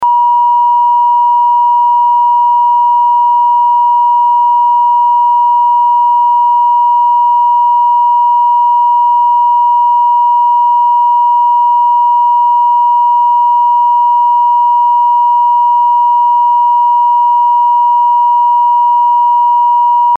EAS Test Audio Downloads
853 Hz Tone